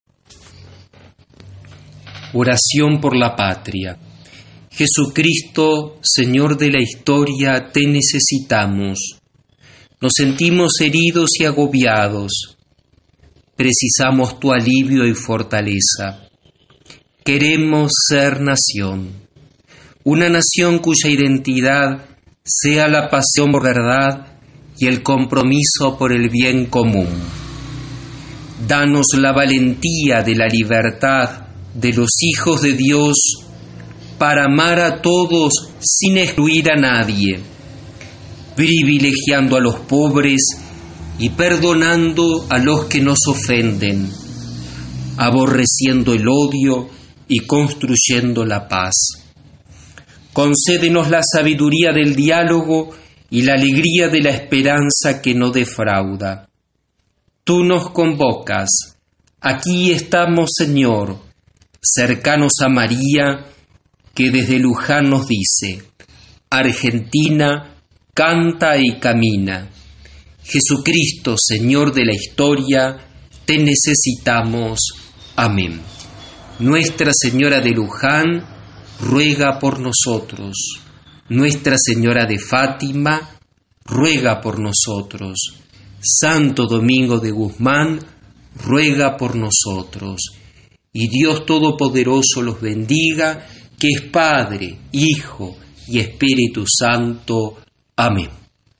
Fue desde cada hogar y el ambiente que cada uno de los integrantes del grupo de oración eligió en su aislamiento.
Lo que hizo que se sumaran voces femeninas dando realce al seguimiento desde cada hogar.
Se acompaña el audio de los cinco Misterios dolorosos.